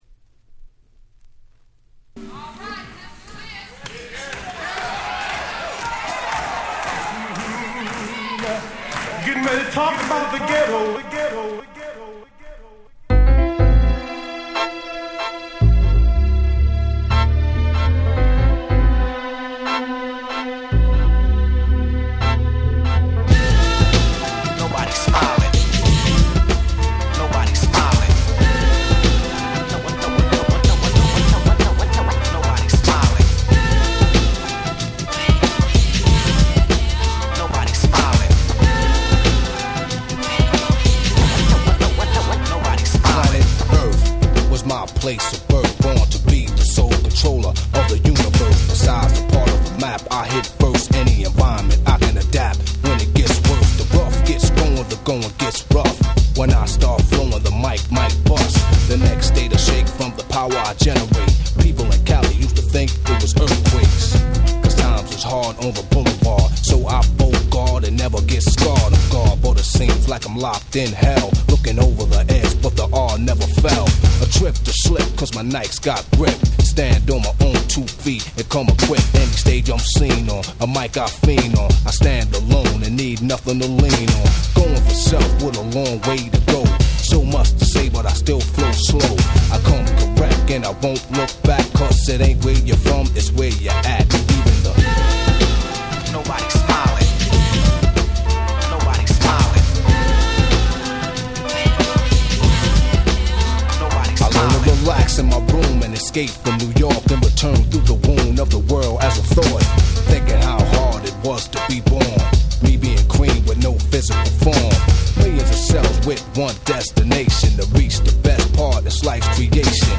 90' Smash Hit Hip Hop !!
90's Boom Bap ブーンバップ